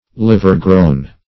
\Liv"er-grown`\